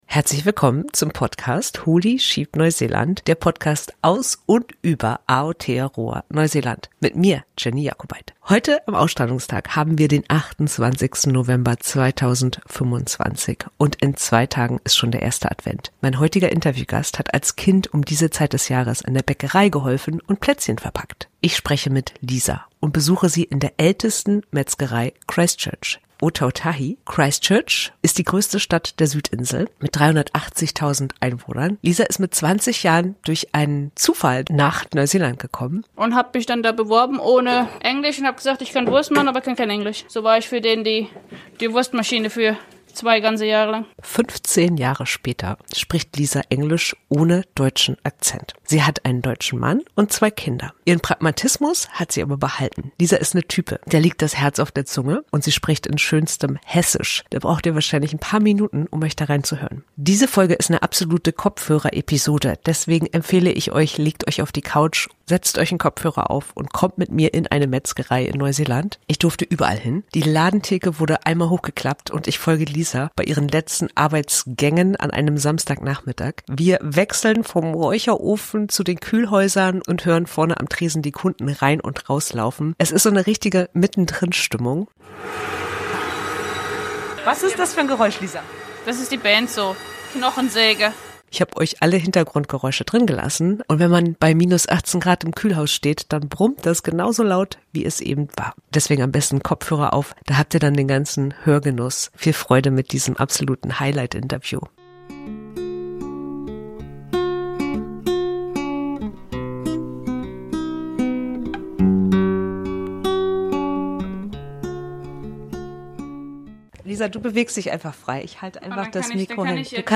Diese Folge ist eine echte Kopfhörer-Episode: Hintergrundgeräusche, Kühlhaus-Brummen und Kundenstimmen inklusive.